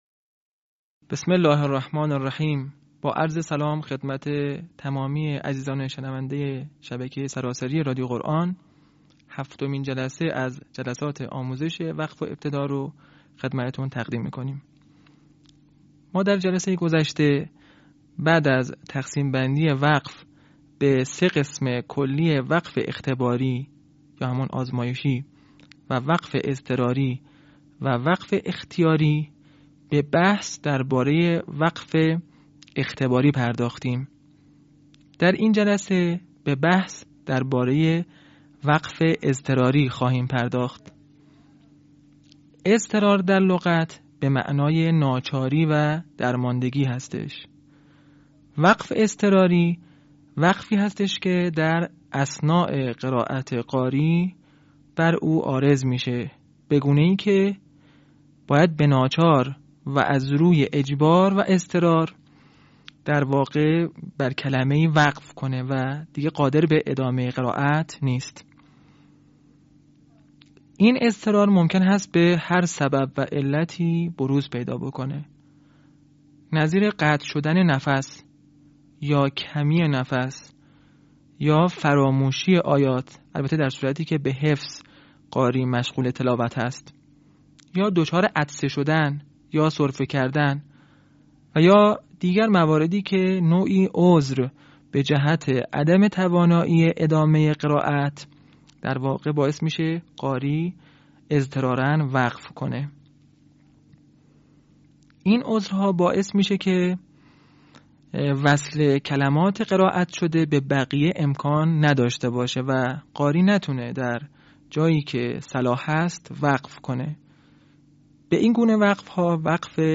صوت | آموزش «وقف اضطراری» در علم وقف و ابتدا